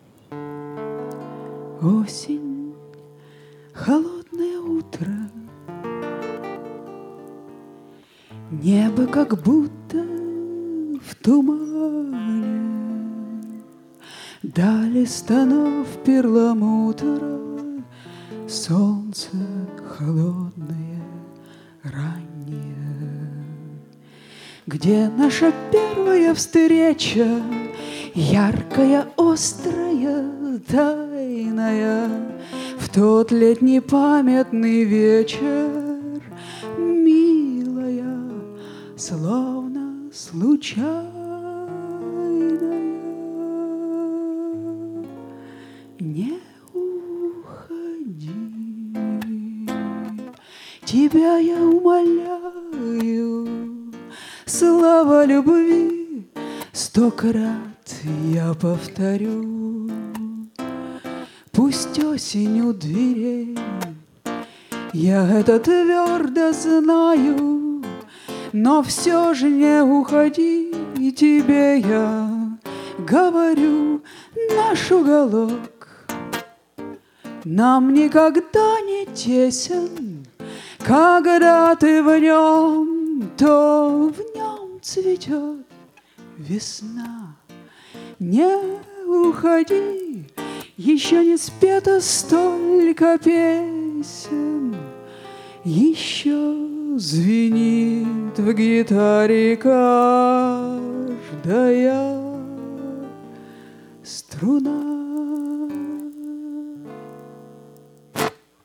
2006 - Субботний (Совершеннолетний слёт #16)